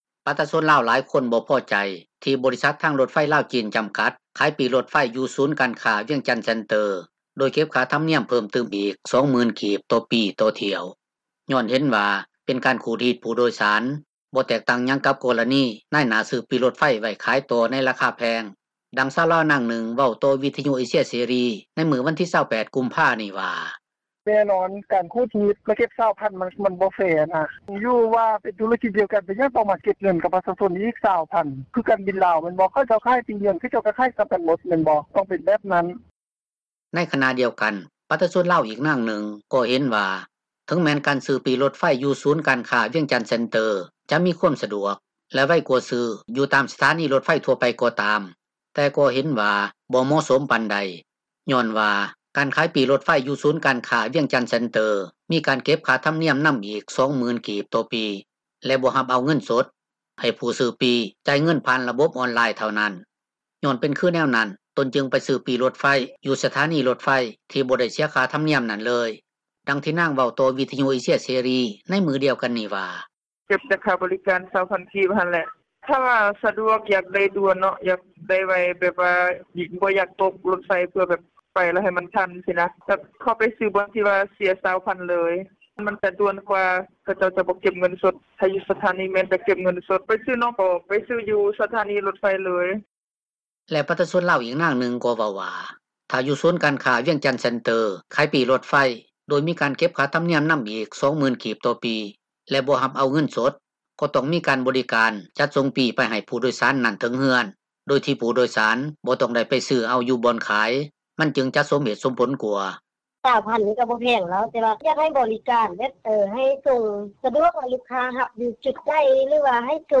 ດັ່ງຊາວລາວ ນາງນຶ່ງເວົ້າຕໍ່ ວິທຍຸເອເຊັຍເສຣີ ໃນມື້ວັນທີ 28 ກຸມພານີ້ວ່າ: